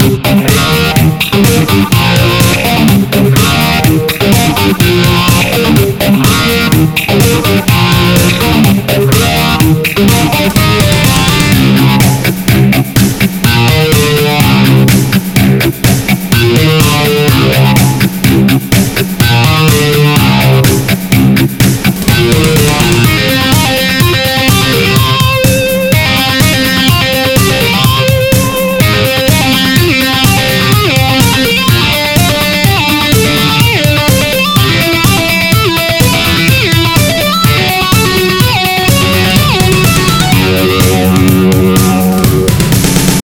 The Boss SD-1 (Super Overdrive stompbox) is part of SimulAnalog Guitar suite, which is a collection of vst plugin with amplifiers and analog processors for guitarists.
Its most important feature is the extremely high precision of the simulation, which is about indistinguishable from the original sound.